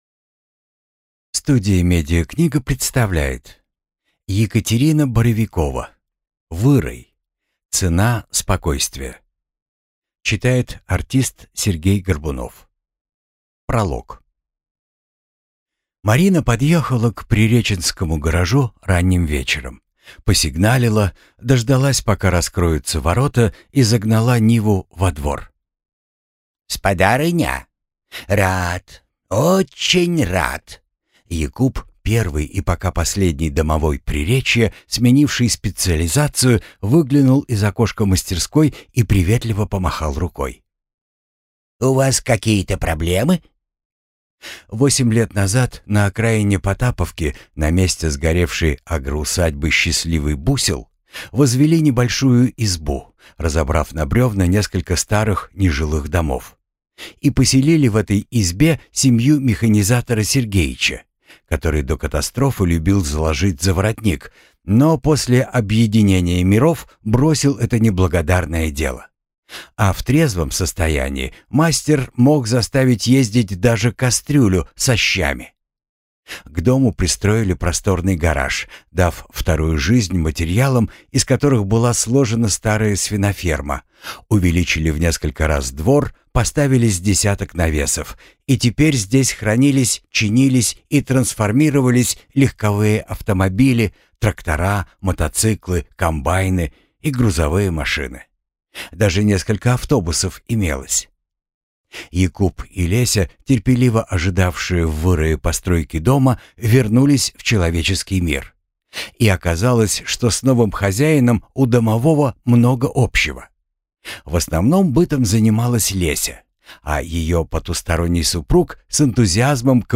Аудиокнига Вырай. Цена спокойствия | Библиотека аудиокниг